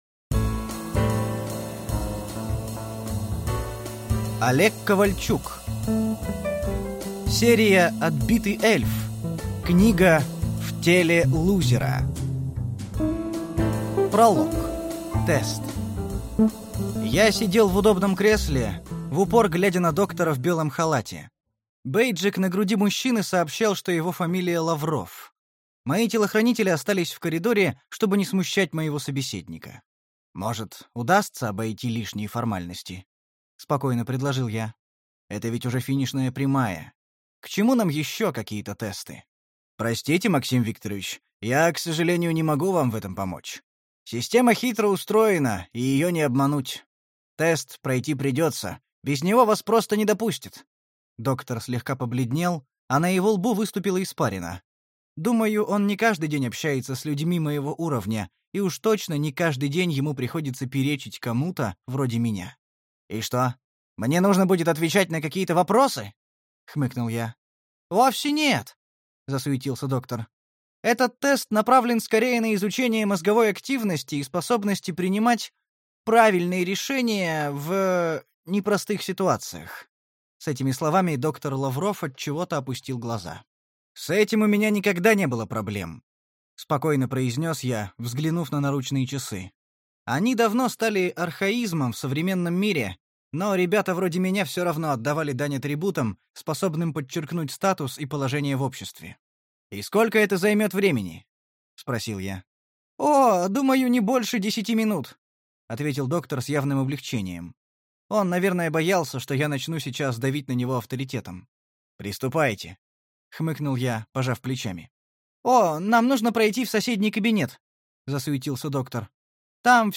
Прослушать и бесплатно скачать фрагмент аудиокниги